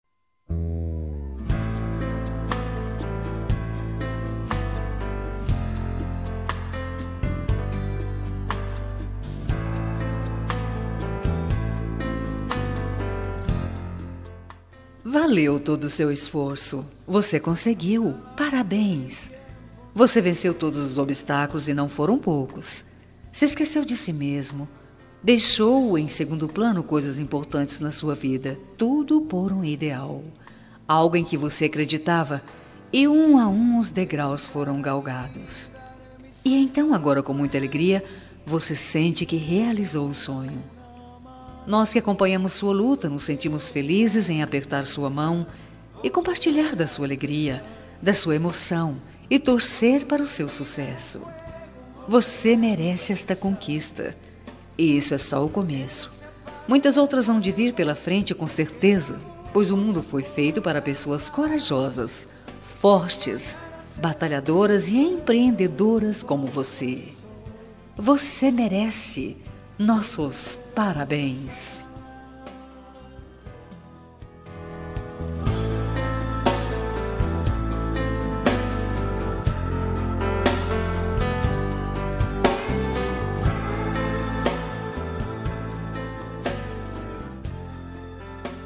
Telemensagem Conquista Vitória – Voz Feminina – Cód: 8146